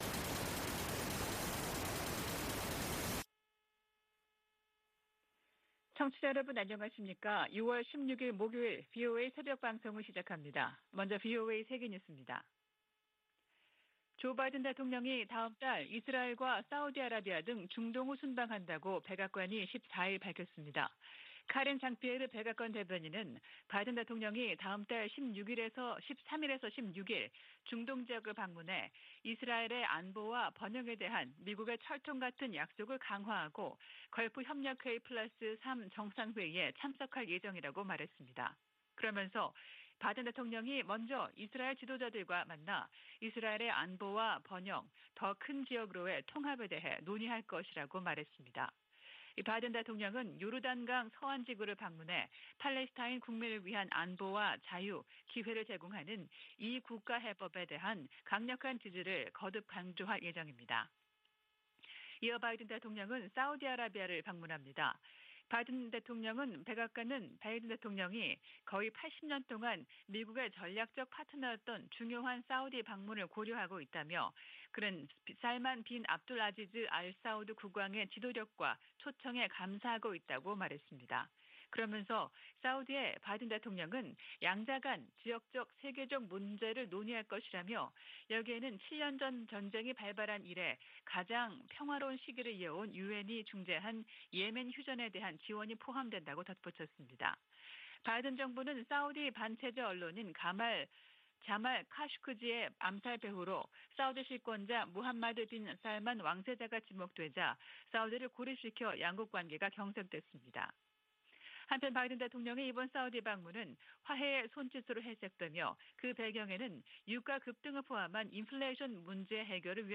VOA 한국어 '출발 뉴스 쇼', 2022년 6월 16일 방송입니다. 미국 정부 대북제재의 근거가 되는 '국가비상사태'가 다시 1년 연장됐습니다. 미 재무부 부장관은 북한의 거듭되는 무력시위에 응해 추가 제재 방안을 면밀히 검토하고 있다고 밝혔습니다. 미국은 한국·일본과 협의해 북한의 도발에 대한 장단기 군사대비태세를 조정할 것이라고 미 국방차관이 밝혔습니다.